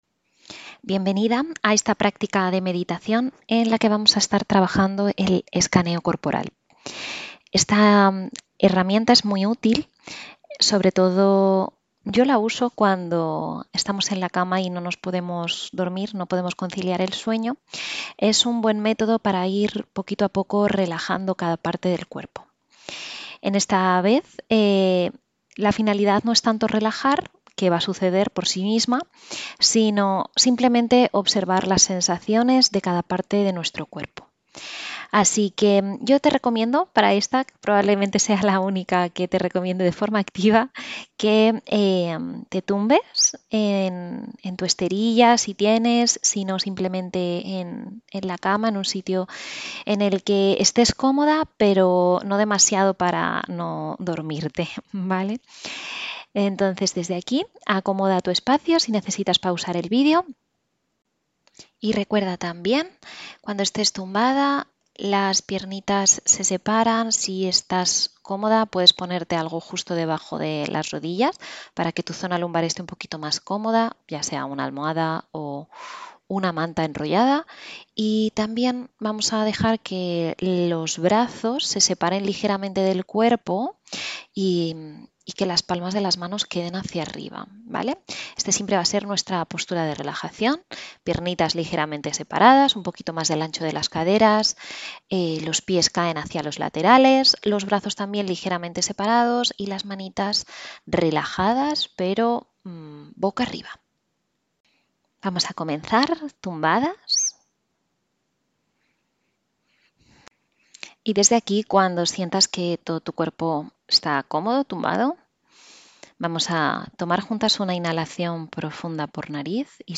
Sesión 3: Meditación de Escaneo Corporal
med-3.-Escaneo-corporal.mp3